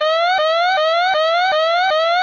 generaterwarning.ogg